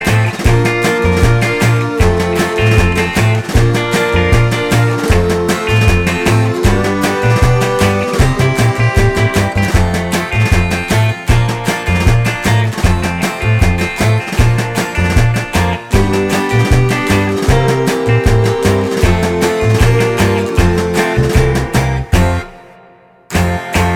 No Harmony Pop (1960s) 1:52 Buy £1.50